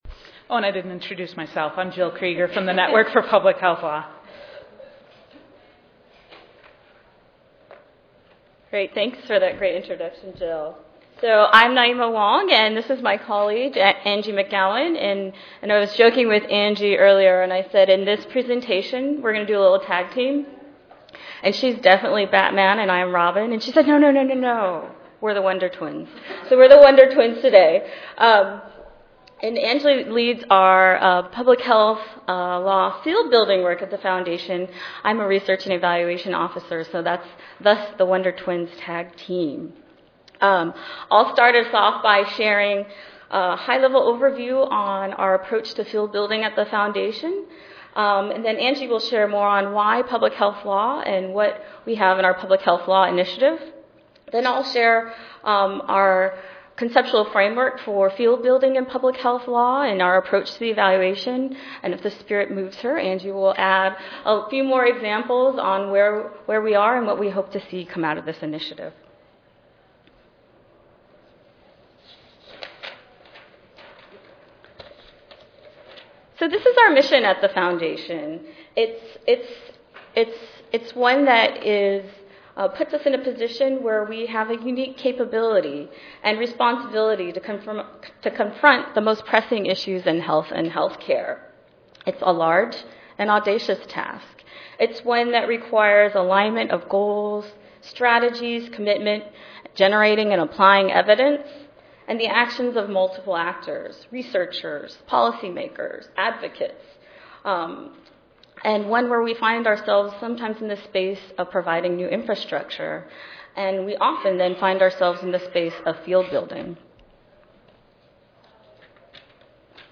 141st APHA Annual Meeting and Exposition (November 2 - November 6, 2013): Building the Field of Public Health Law: Perspectives from Public Health Education, Research, Policy and Practice